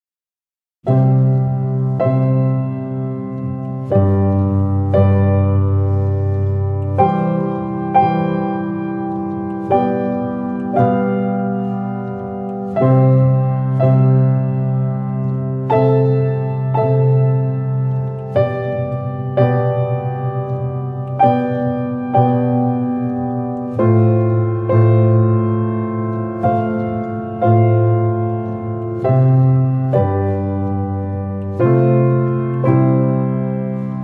Begleitmusik zu den Schwungübungen